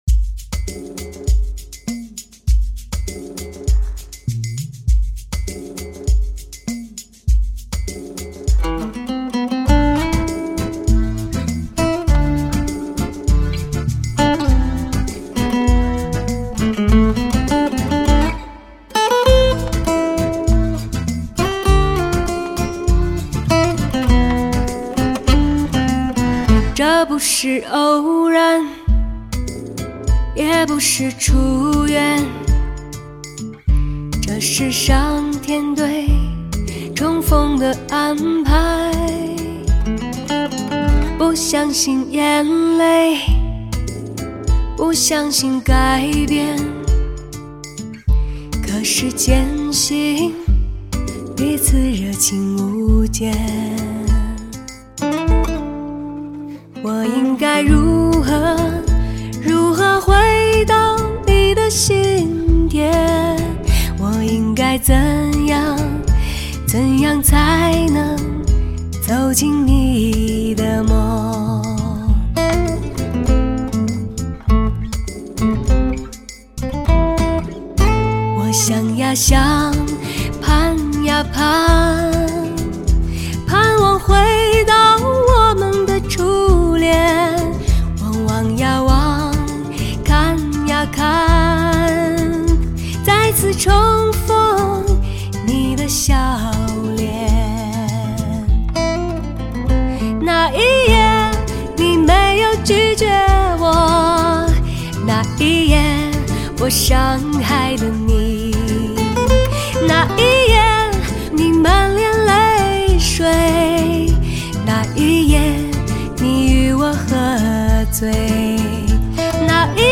Hi－Fi音效美不胜收。